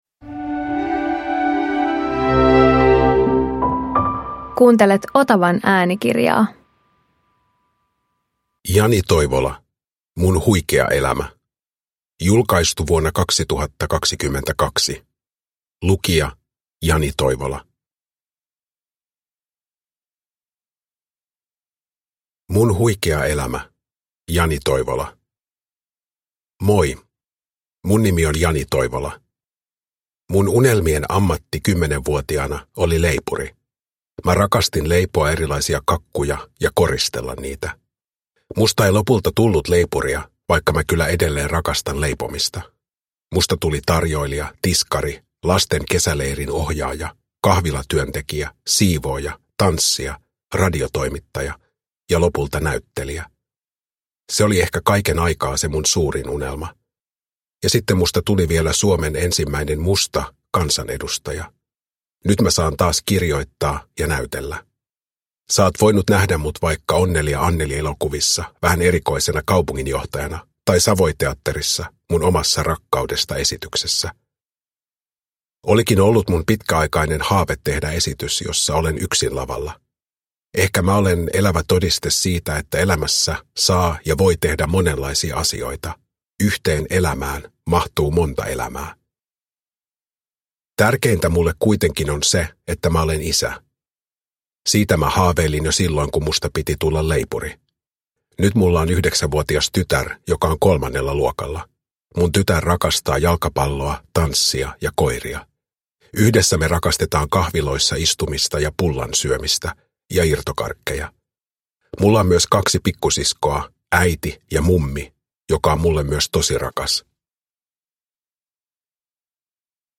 Mun huikea elämä - Jani Toivola – Ljudbok
Uppläsare: Jani Toivola